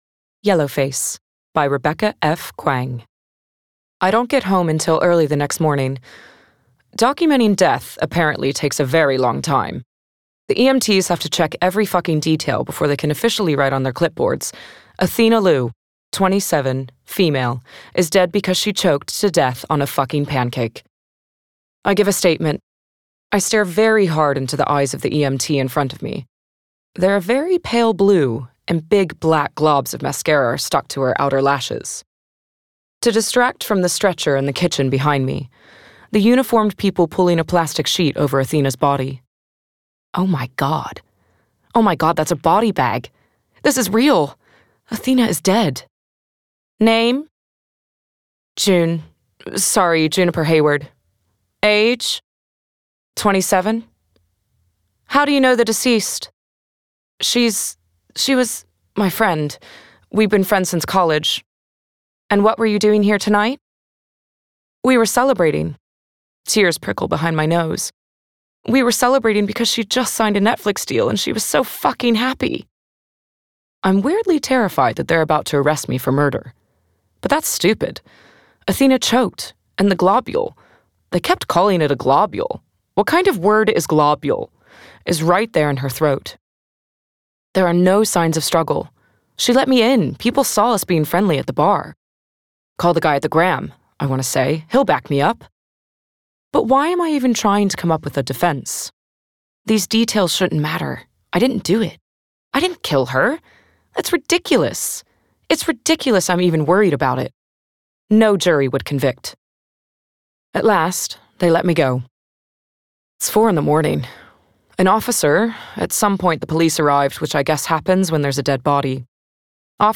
Audiobook.
Emotion, Light
Gender Female